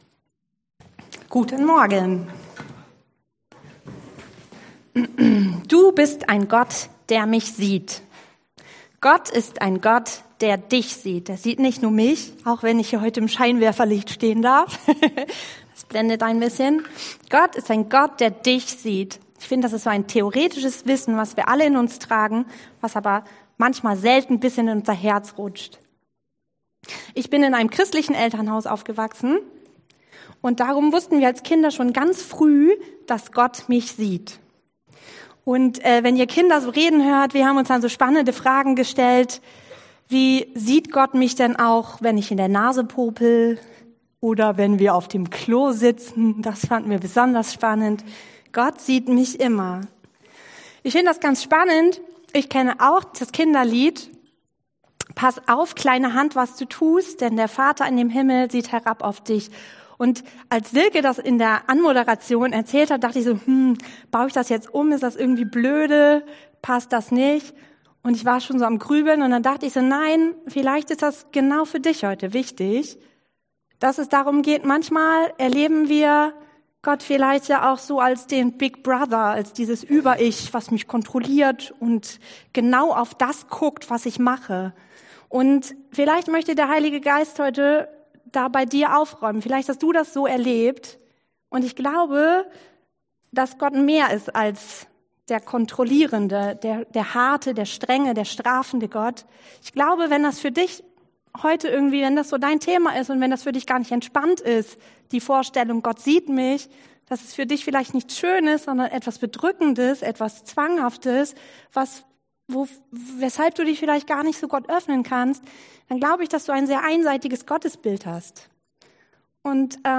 Samuel 1 und 2,18-21 Dienstart: Predigt « Gutes annehmen …und an meinem Leben interessiert ist.